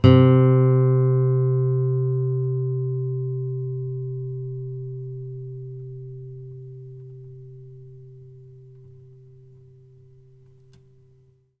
bass-01.wav